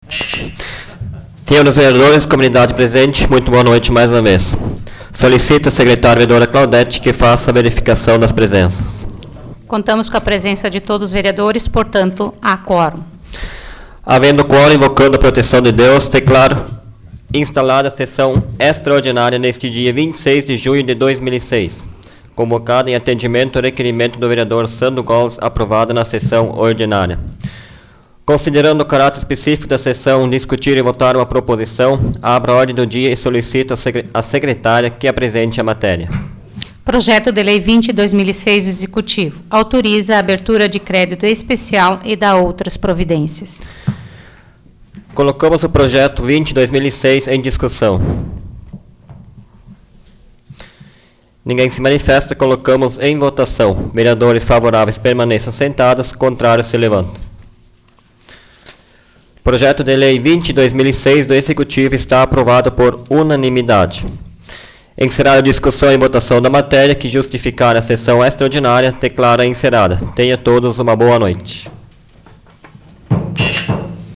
Áudio da 20ª Sessão Plenária Extraordinária da 12ª Legislatura, de 26 de junho de 2006